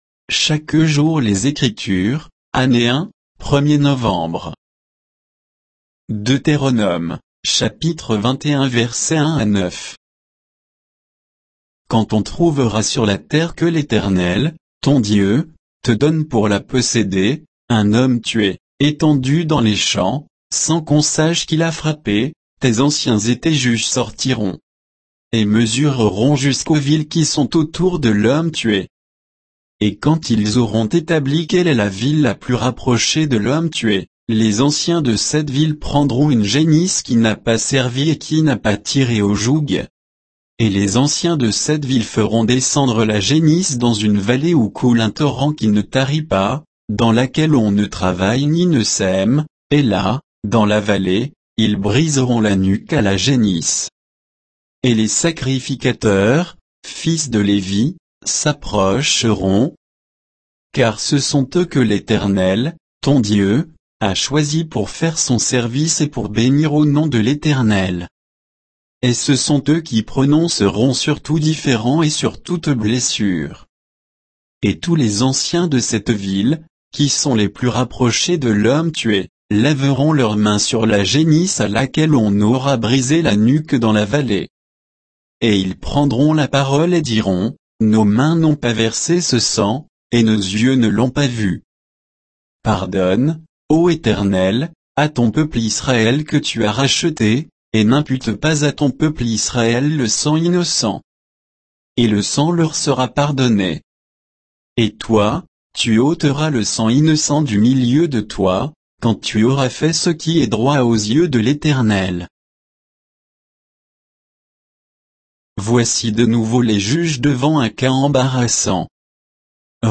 Méditation quoditienne de Chaque jour les Écritures sur Deutéronome 21